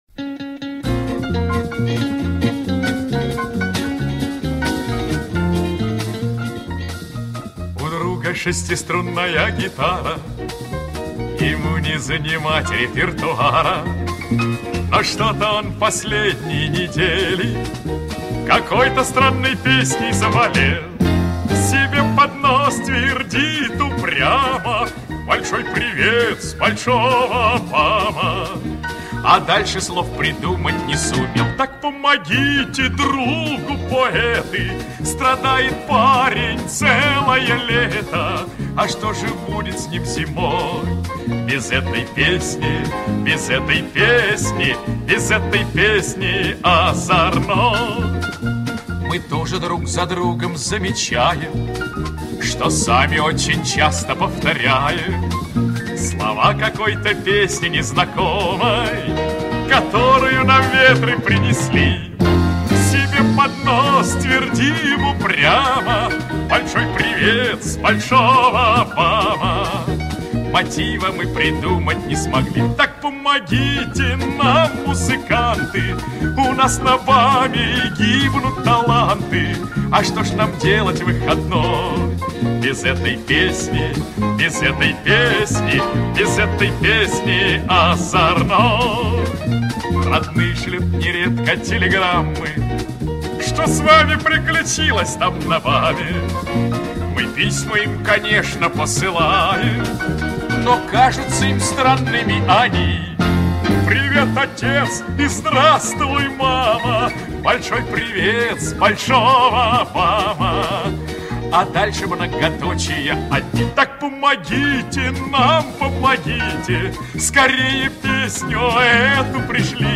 Музыкальное сопровождение: Эдуард Хиль — Большой привет с большого БАМа (муз. А. Морозова — сл. В. Гина)